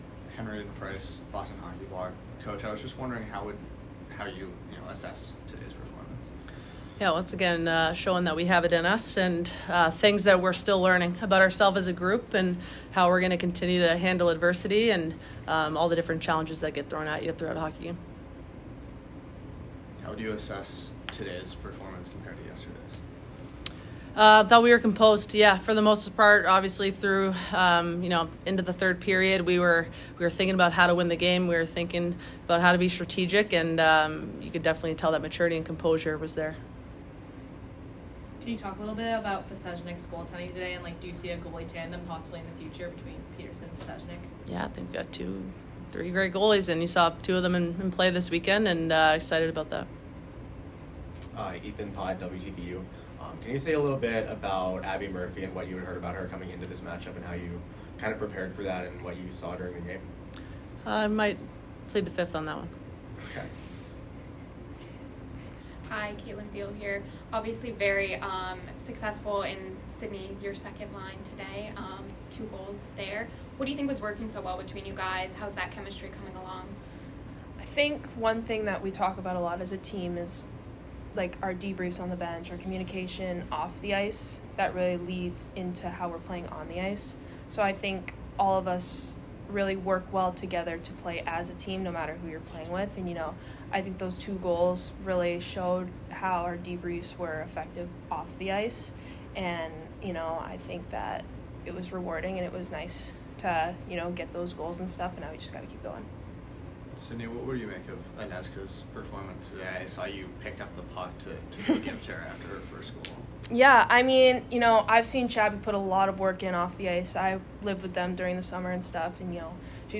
Women's Ice Hockey / Minnesota Postgame Interview (10-4-25)